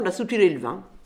Saint-Hilaire-des-Loges
Catégorie Locution